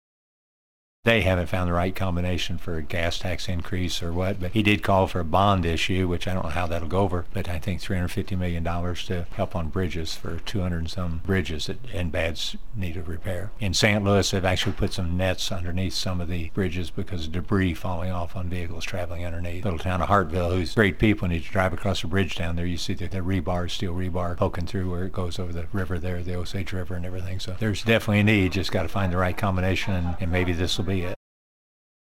3. Senator Cunningham also says infrastructure improvements are high on the governor’s list.